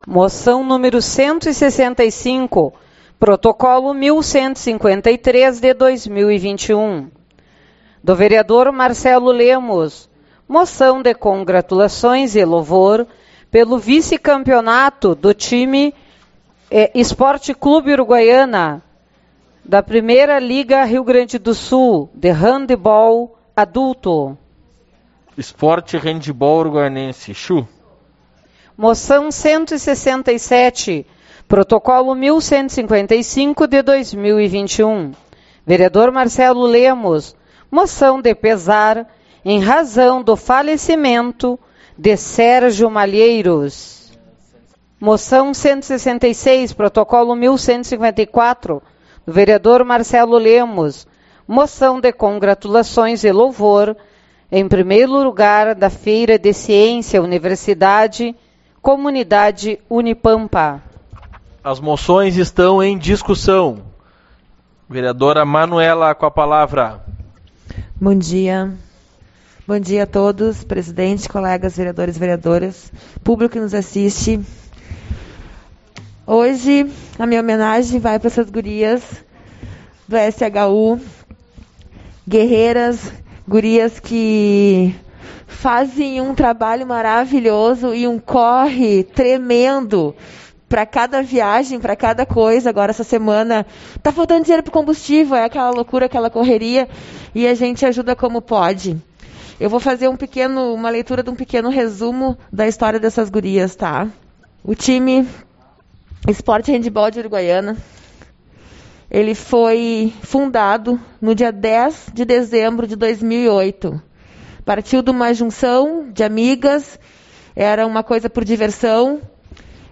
18/11 - Reunião Ordinária